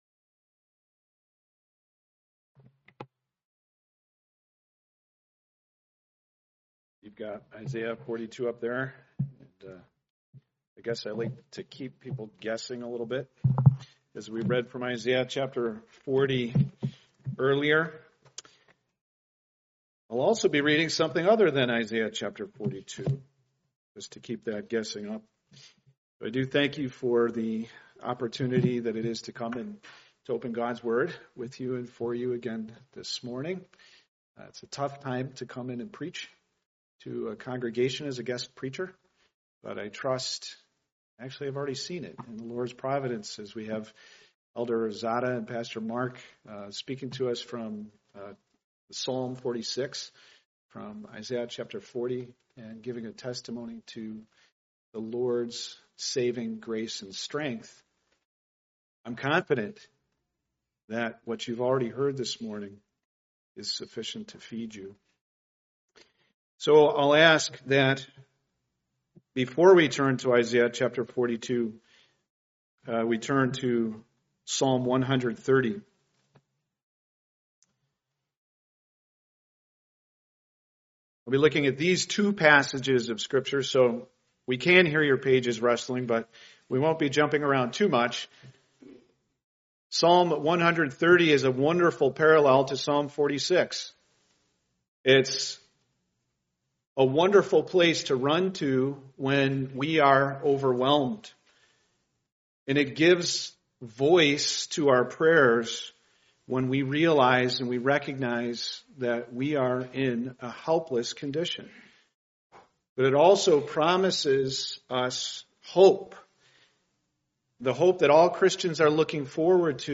Part of the Guest Speakers series, preached at a Morning Service service.